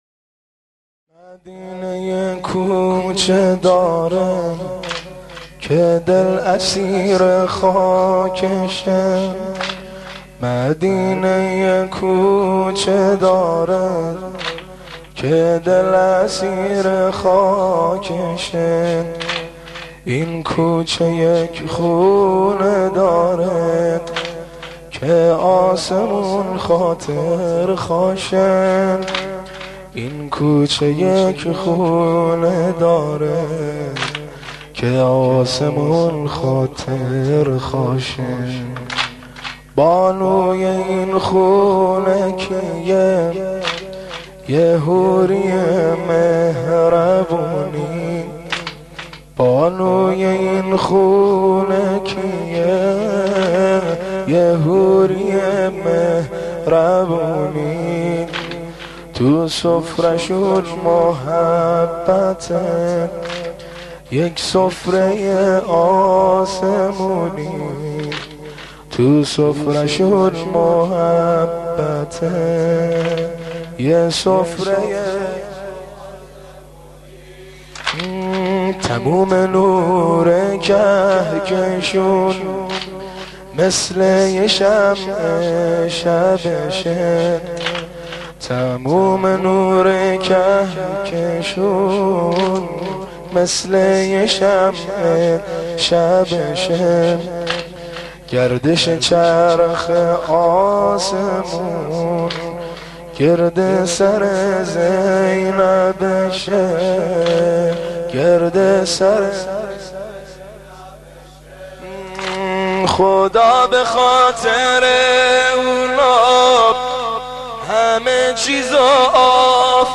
قالب : سنگین